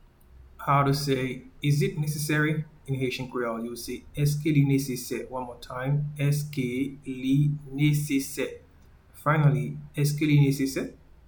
Pronunciation:
Is-it-necessary-in-Haitian-Creole-Eske-li-nesese.mp3